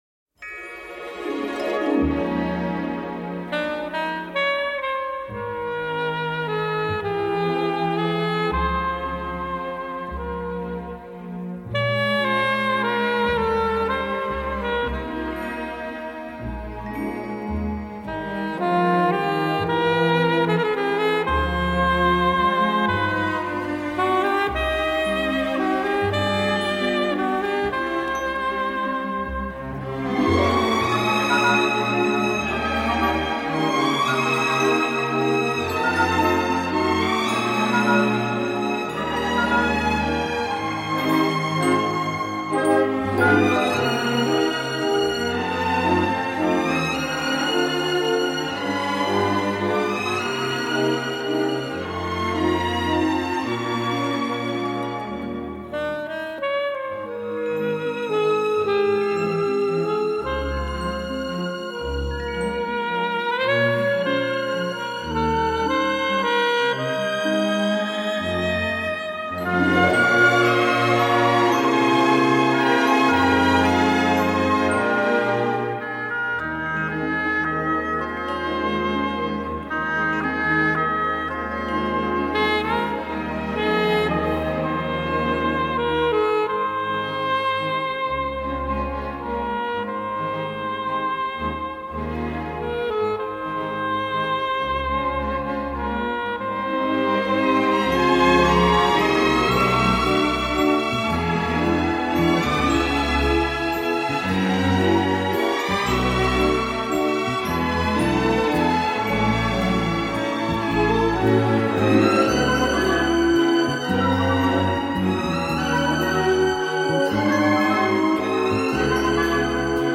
une partition au classicisme élégant et distingué